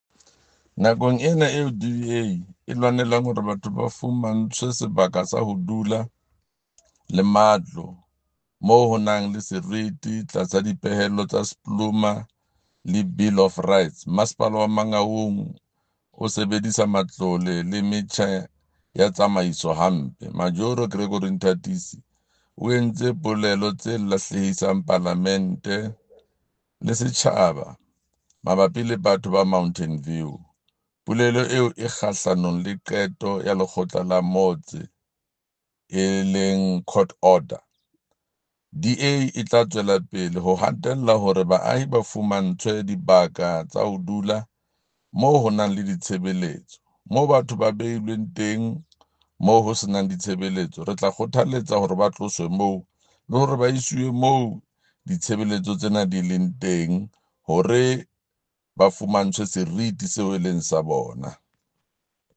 Sesotho soundbite by Jafta Mokoena MPL with images here, here and here